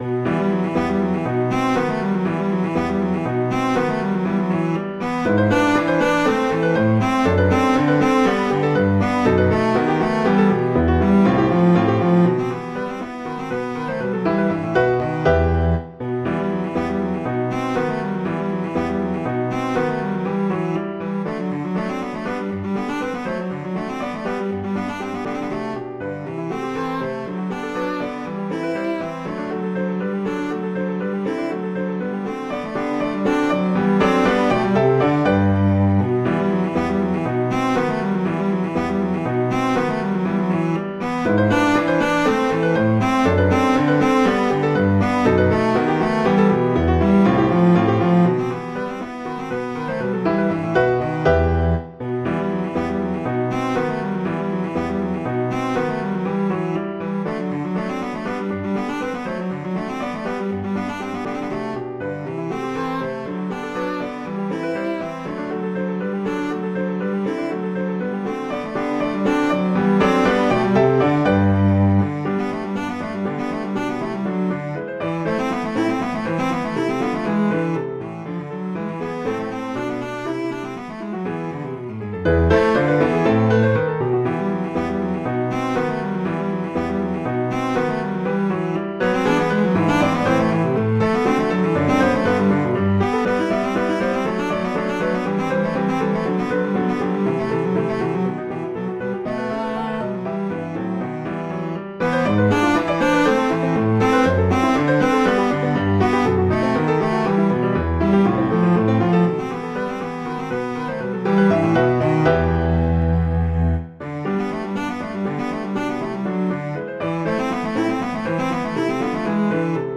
cello and piano
classical
G major, E minor